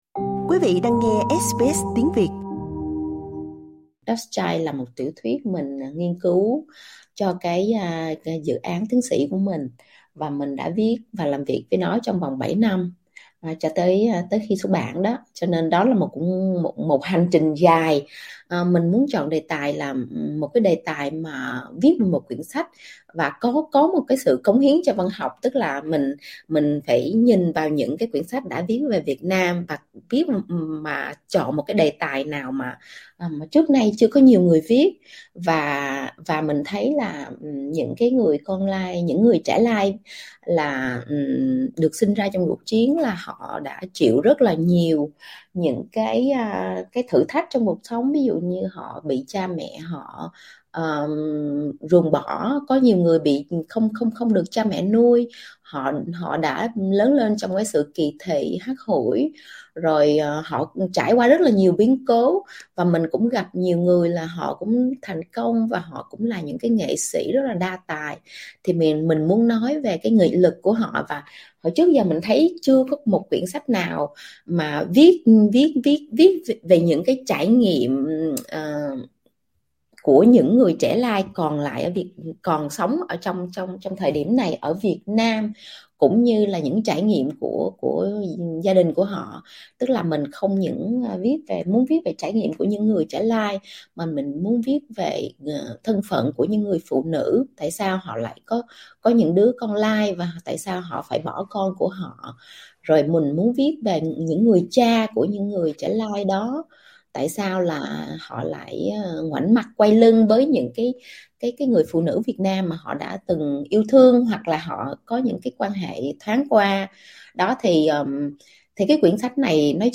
Trò chuyện với SBS Tiếng Việt, nhà văn Quế Mai chia sẻ về cuốn sách mới ra mắt ‘Dust Child’ - tạm dịch là 'Trẻ lai', cũng như hành trình văn chương từ khởi đầu khiêm tốn của chị.